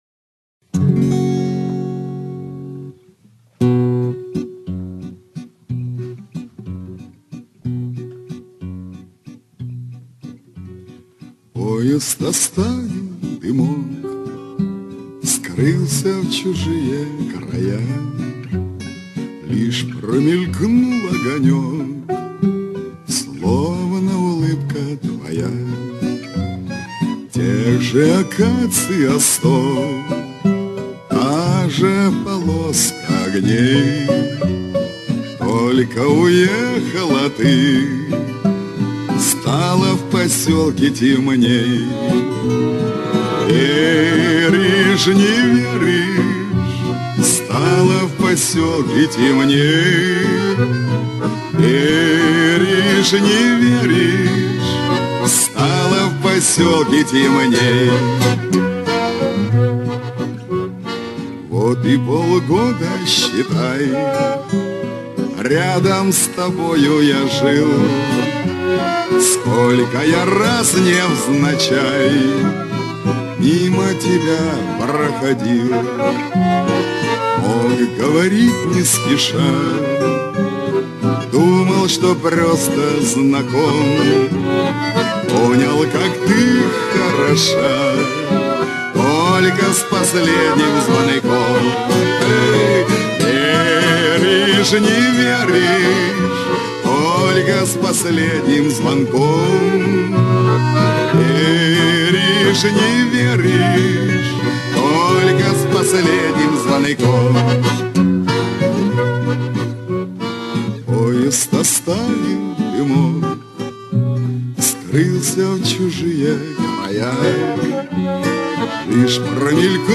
Шансонное исполнение